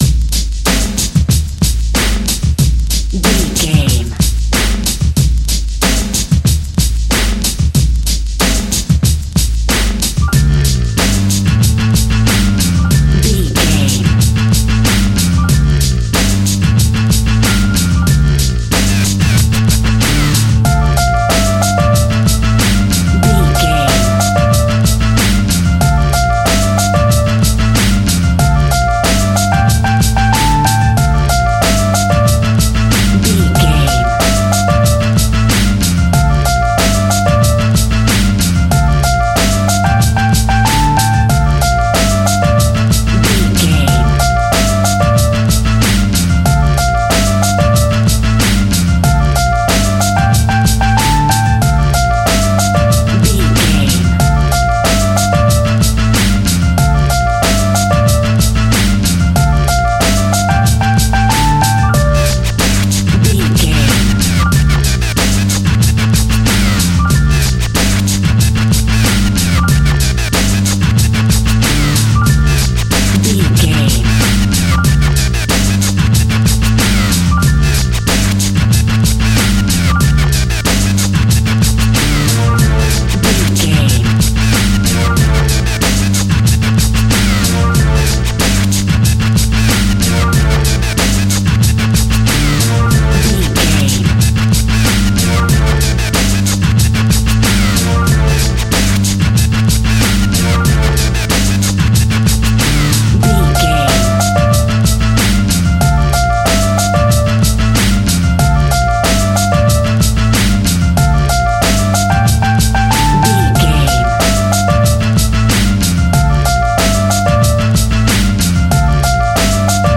Aeolian/Minor
D
hip hop instrumentals
downtempo
synth lead
synth bass
synth drums
hip hop loops